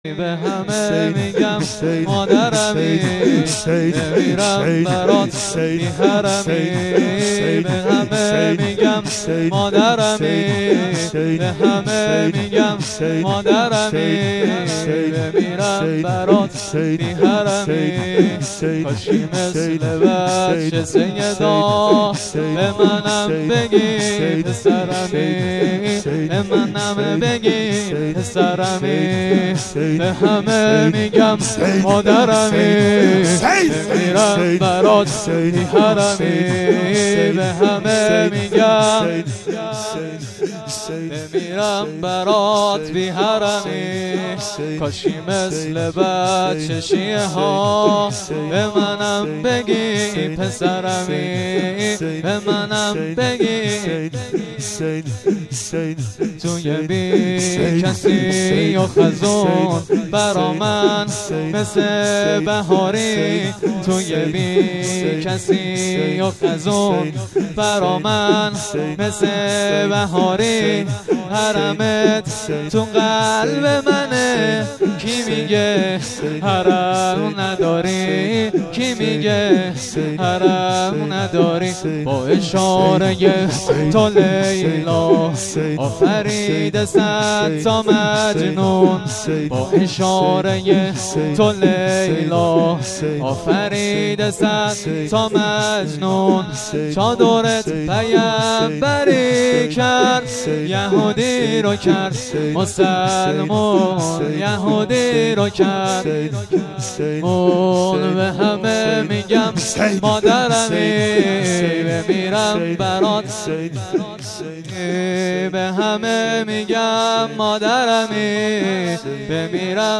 فاطمیه دوم 97